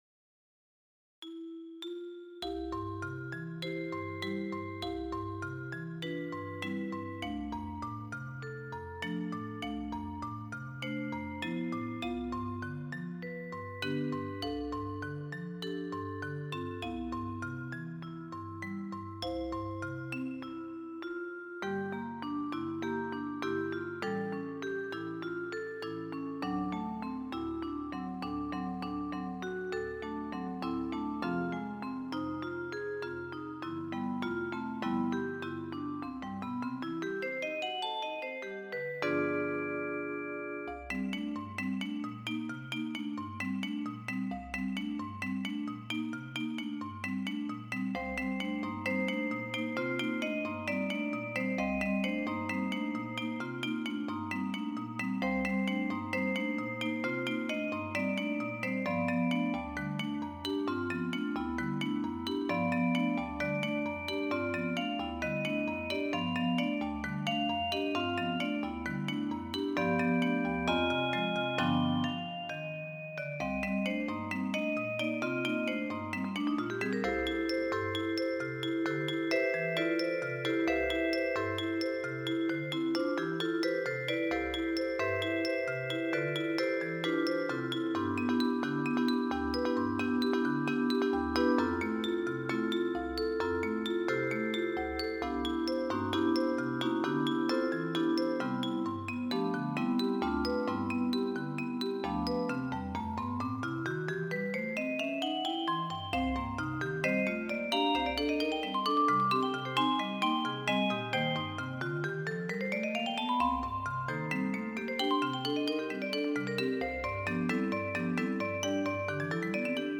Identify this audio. Instrumentation: percussions